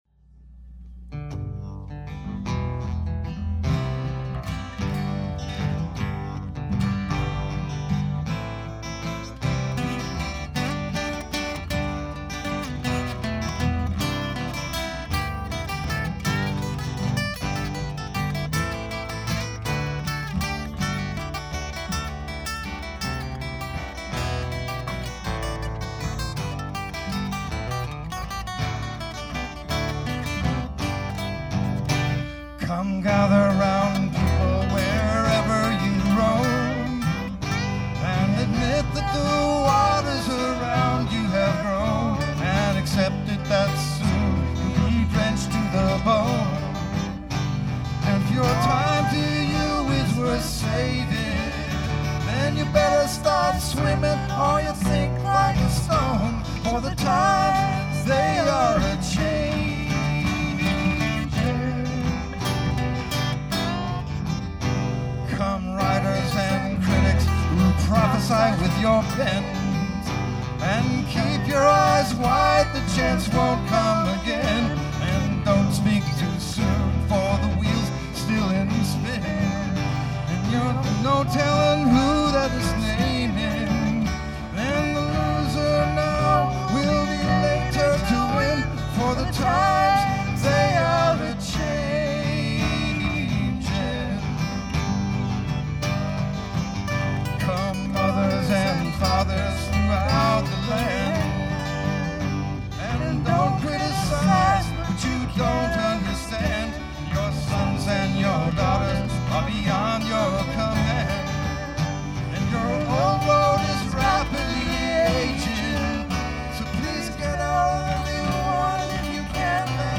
Performances